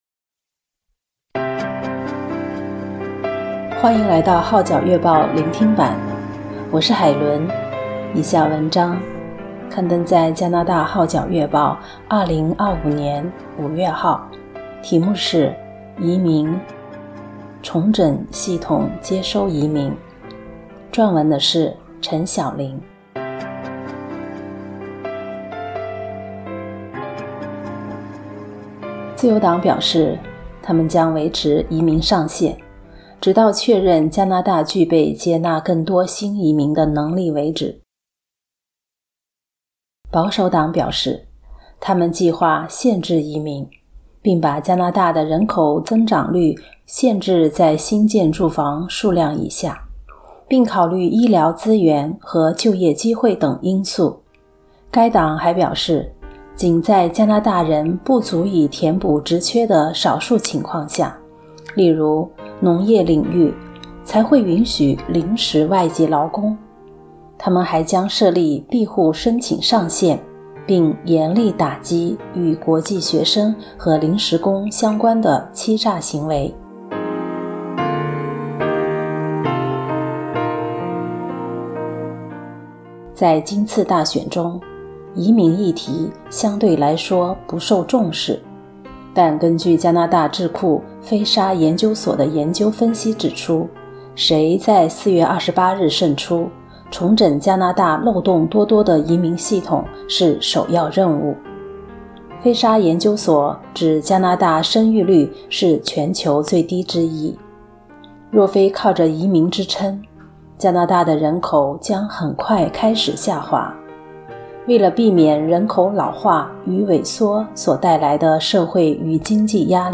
聆聽版/Audio移民：重整系統接收移民